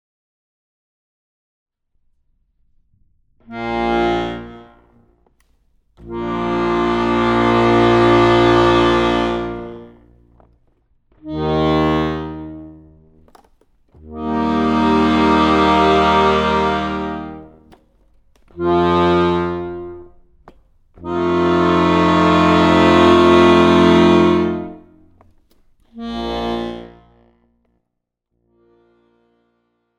Besetzung: Tuba
24 - Stimmtöne
24_stimmtoene.mp3